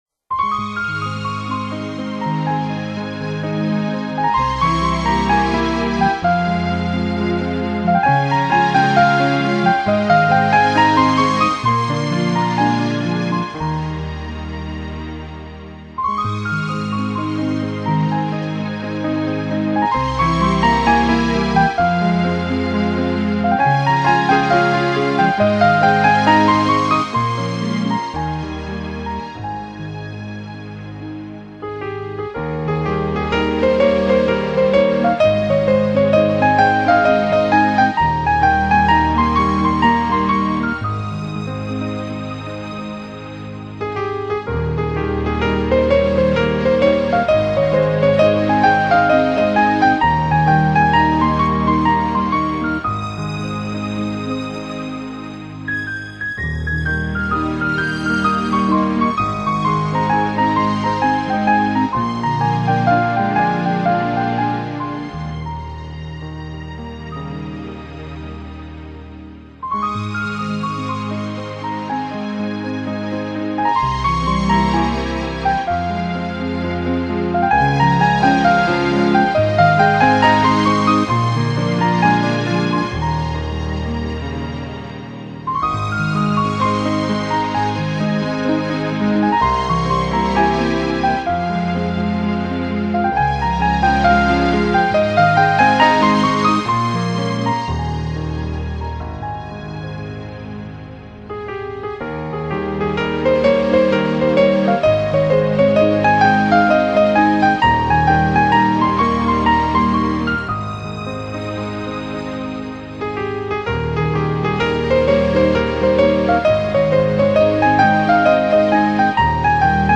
Genre:New Age,Instrumental,Piano
凄美的琴音在满溢的情境夜里听来